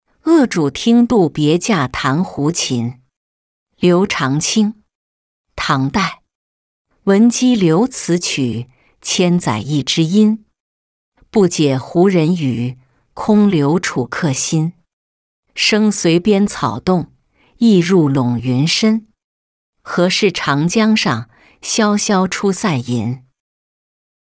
鄂渚听杜别驾弹胡琴-音频朗读